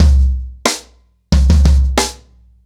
Wireless-90BPM.37.wav